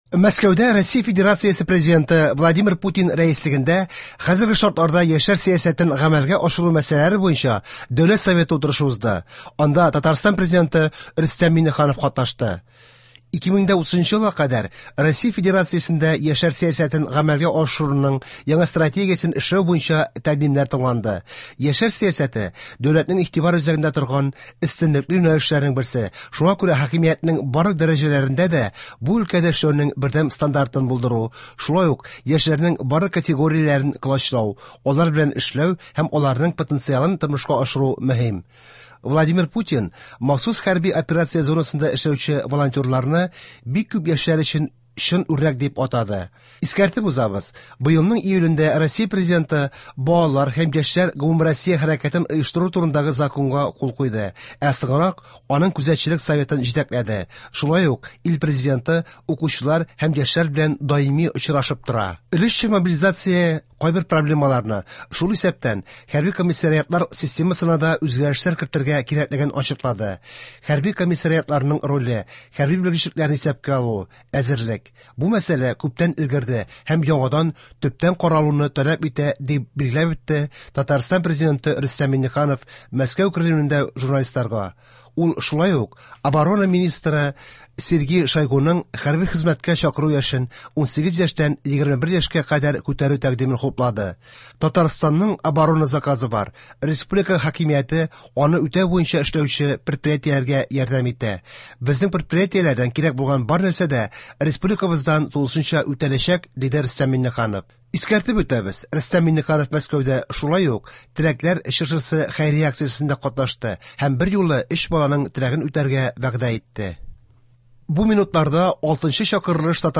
Яңалыклар (23.12.22)